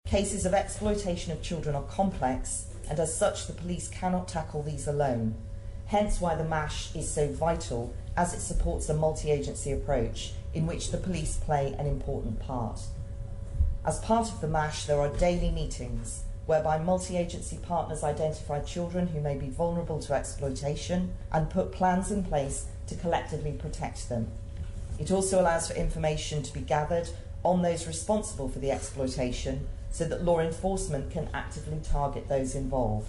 That was the question posed by Douglas North MHK David Ashford in the House of Keys this week.